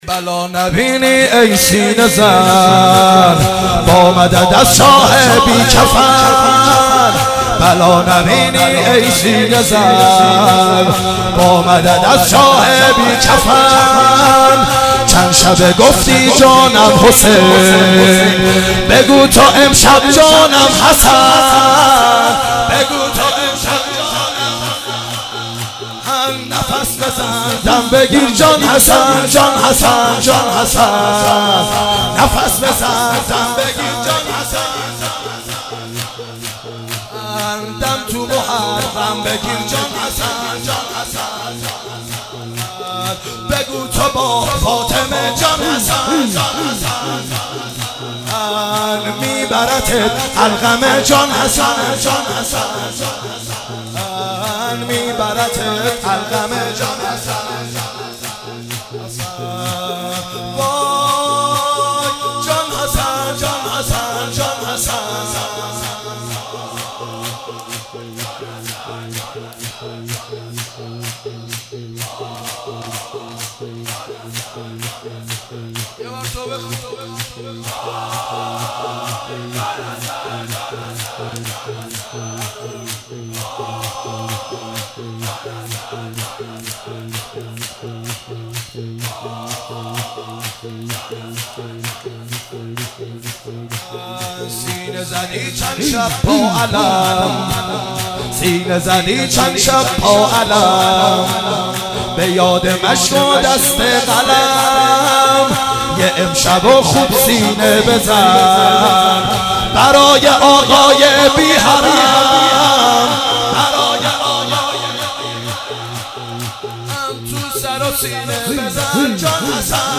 شور .